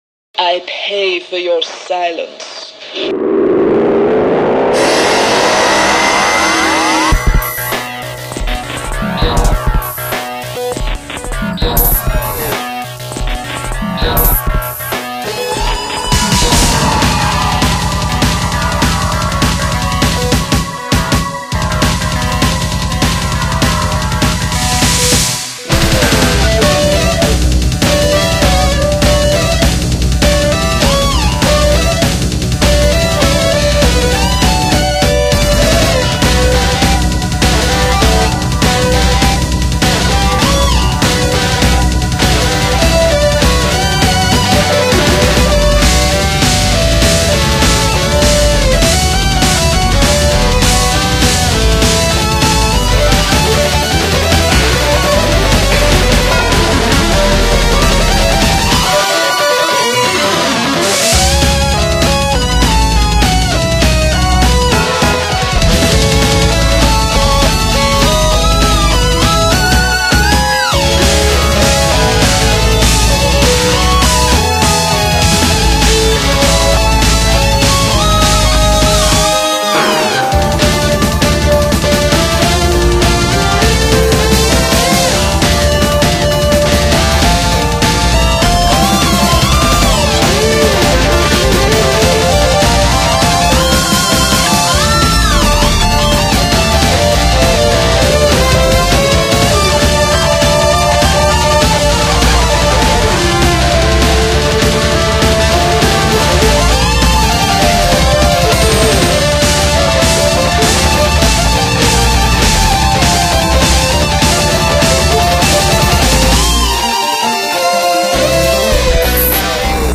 дауж,тут скрипку помучали как надо